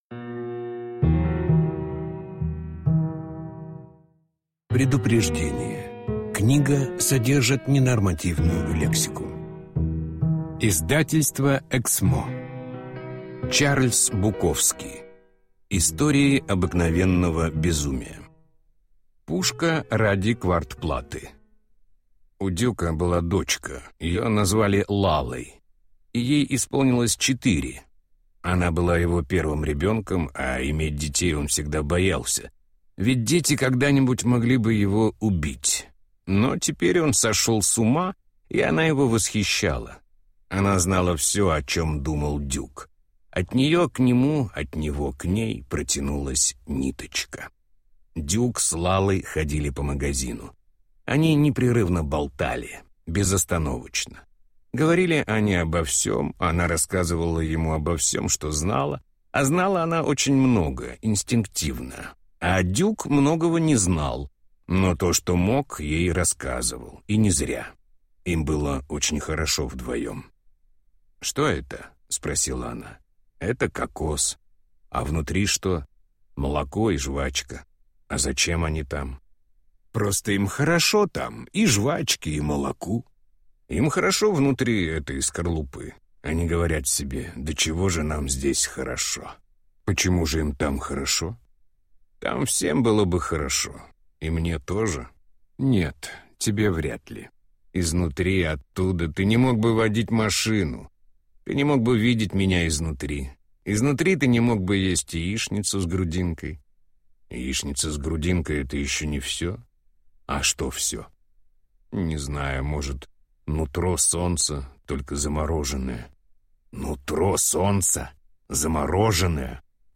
Аудиокнига Истории обыкновенного безумия | Библиотека аудиокниг